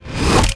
VEC3 Reverse FX
VEC3 FX Reverse 22.wav